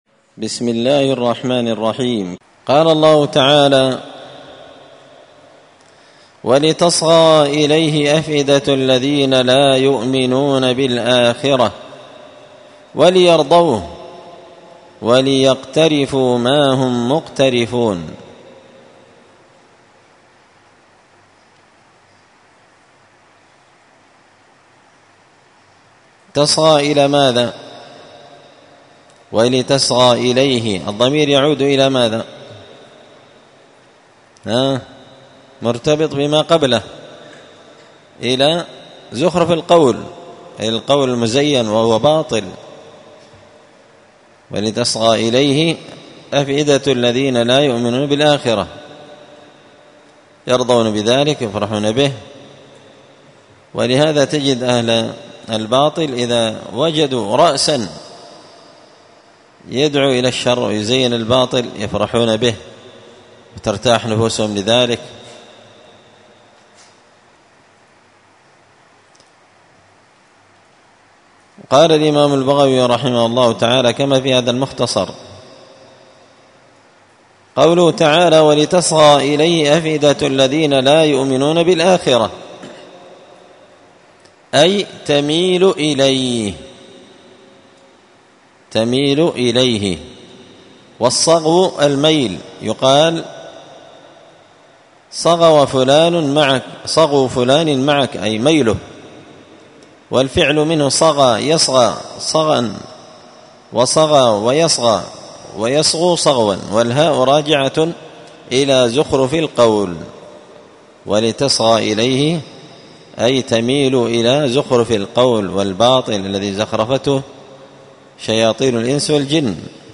مسجد الفرقان قشن_المهرة_اليمن